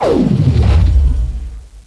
slowmo_on.ogg